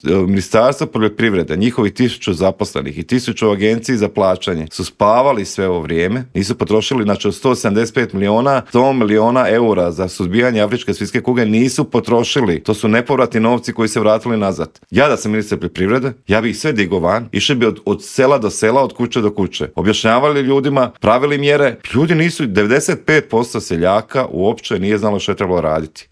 ZAGREB - U Intervjuu Media servisa gostovao je Mario Radić iz Domovinskog pokreta koji se osvrnuo na optužbe premijera Andreja Plenkovića da iza prosvjeda svinjogojaca stoji upravo njegova stranka, otkrio nam s kim bi DP mogao koalirati nakon parlamentarnih izbora, a s kim nikako i za kraj rezimirao 2023. godinu koja lagano ide kraju.